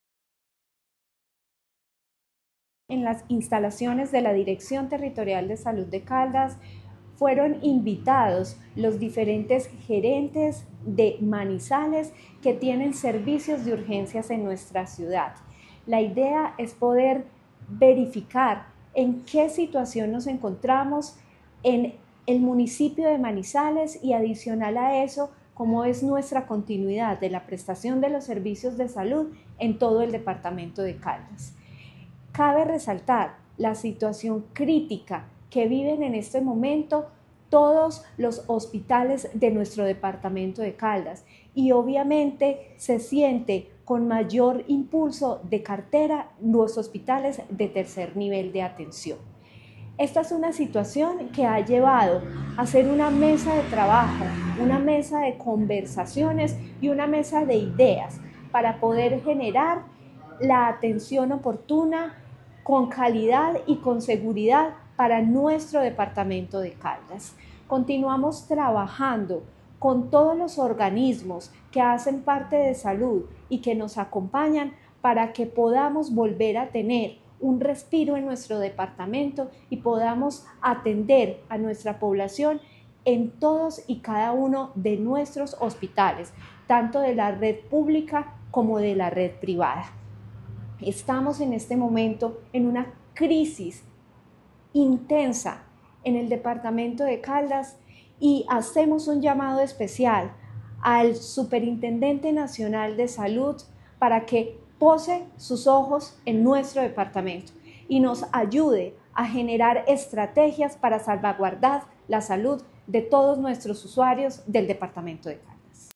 Natalia Castaño Díaz, directora de la DTSC.
AUDIO-NATALIA-CASTANO-DIAZ-DIRECTORA-DTSC-2.mp3